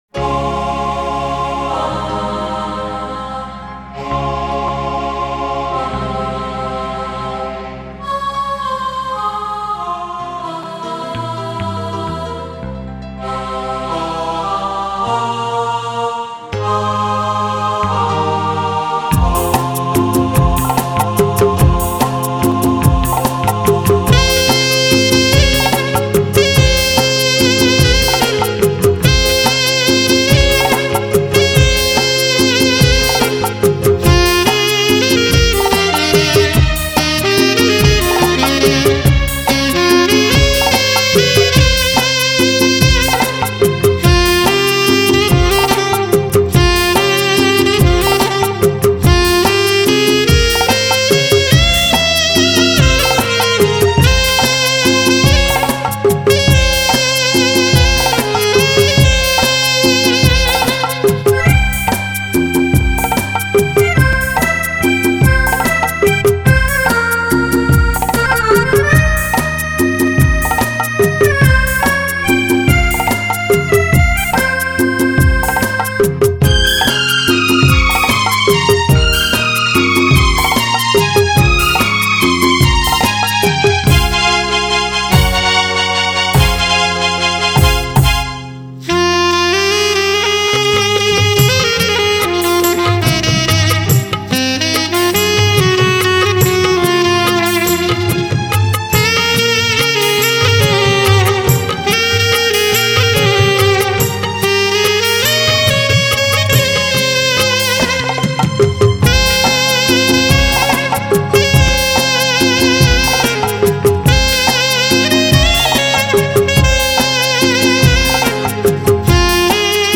Category: Odia Karaoke instrumental Song